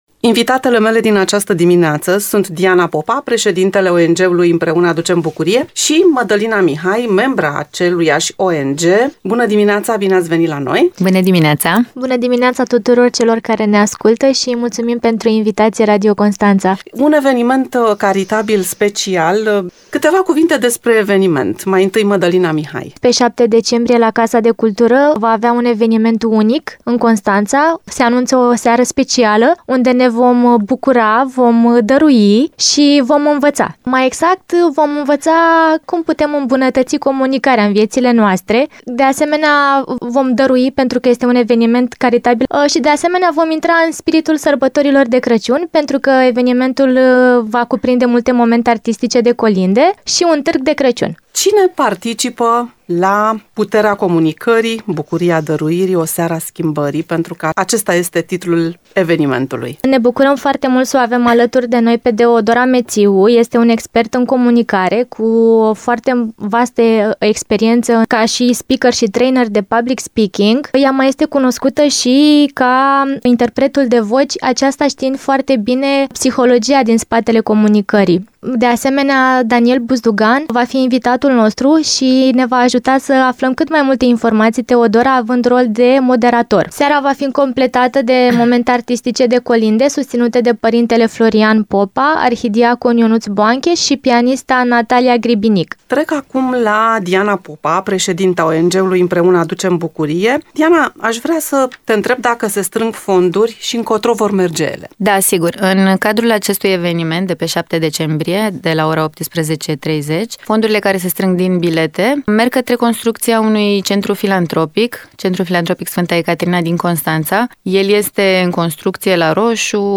Despre această acțiune, au vorbit, la „Interviul Dimineții”